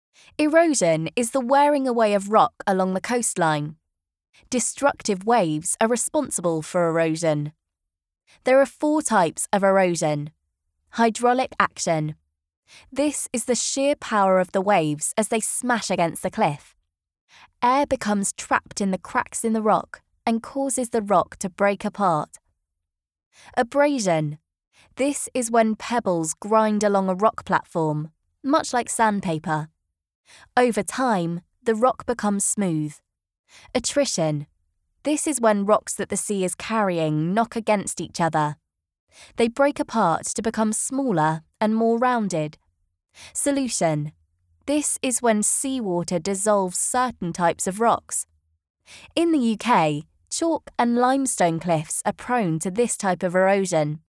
Female
My voice is warm, smooth and clear with a playing age from teens to early 30s.
Commercial Reel
Words that describe my voice are Warm, Youthful, Clear.